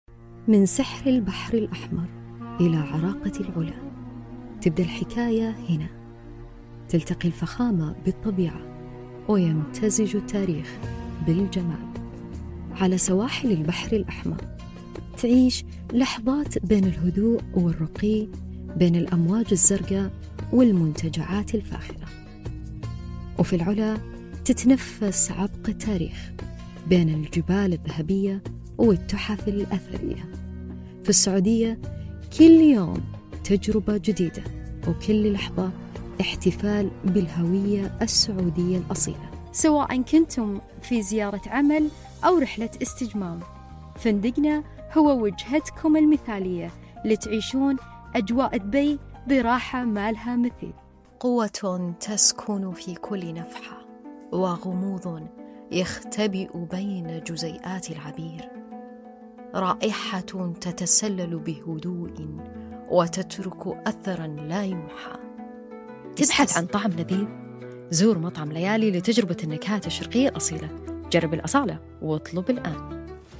Arabic (Saudi Arabia), Middle Eastern, Female, Home Studio, 20s-40s, Based in LA